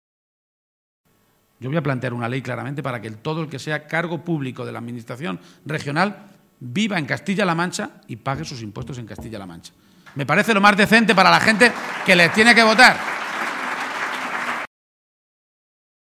Audio Page en Mondejar-2